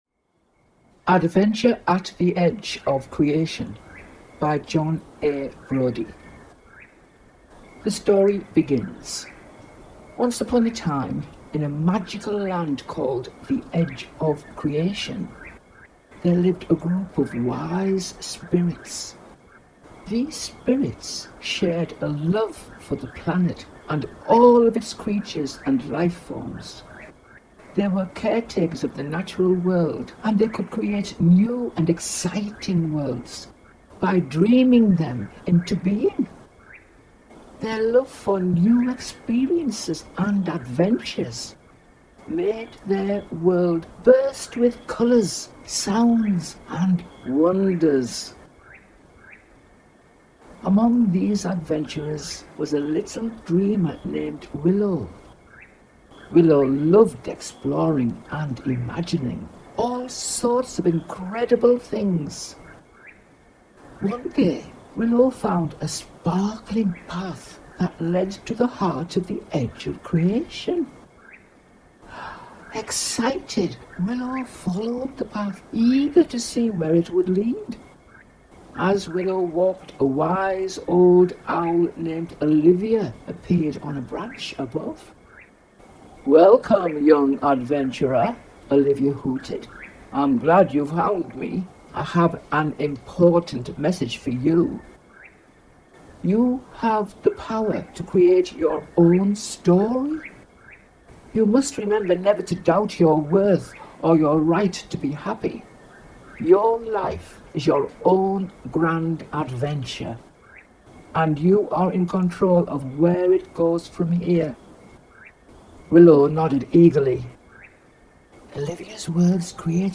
This classic adventure tale is perfect for all ages—designed to entertain, inspire, and ignite young minds to dream big and embrace their creative power.